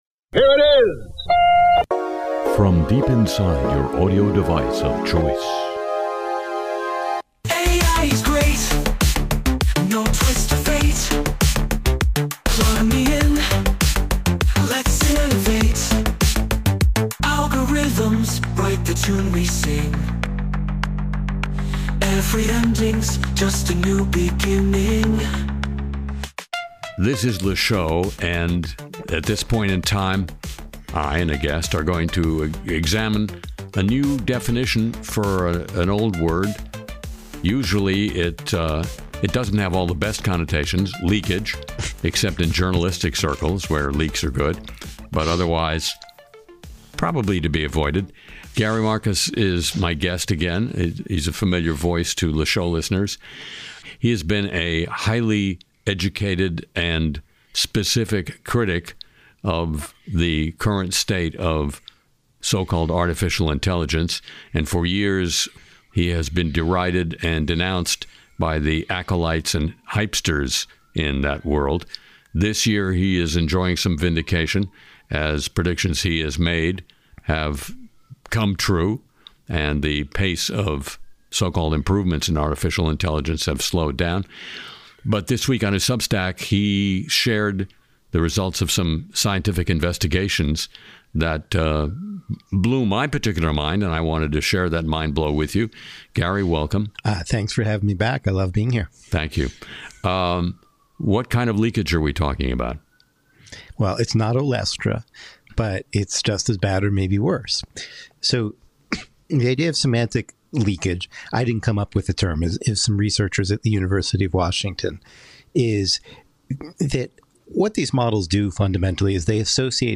Harry Shearer talks with AI expert Gary Marcus about why slick-sounding machines aren’t actually smart, then shares a festive Holiday Playlist for the season.